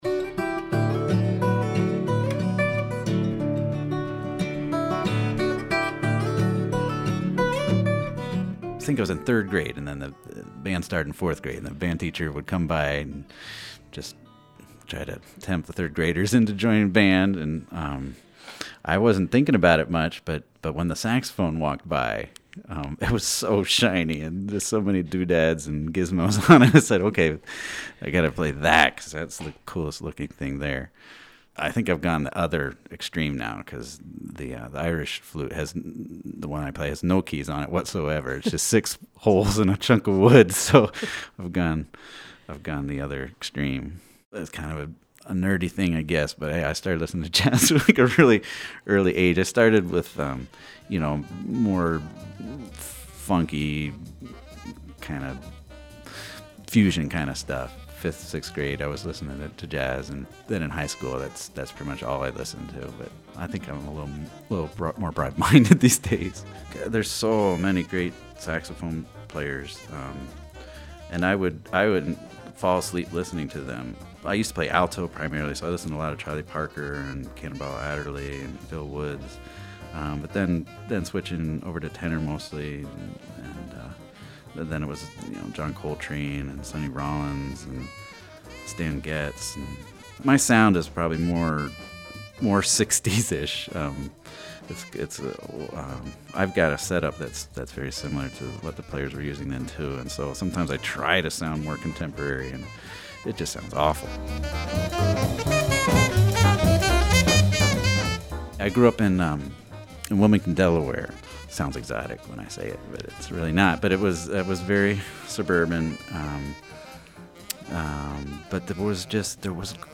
multi-instrumentalist